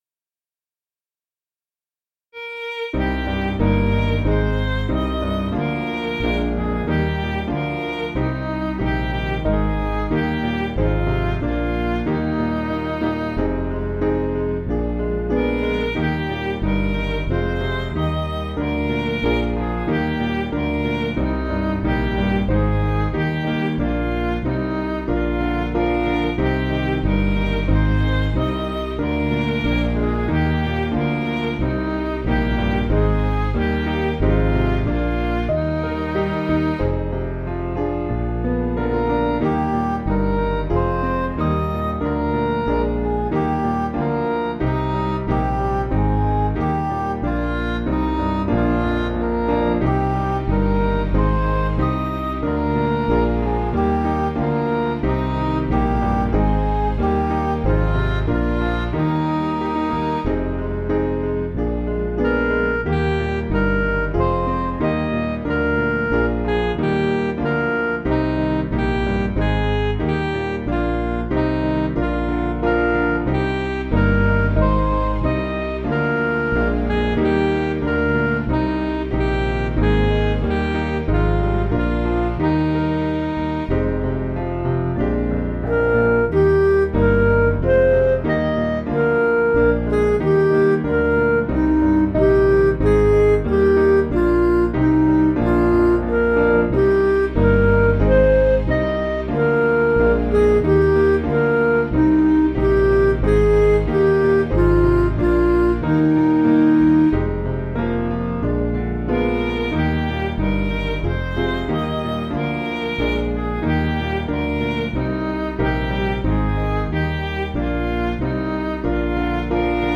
Piano & Instrumental
(CM)   6/Eb
Midi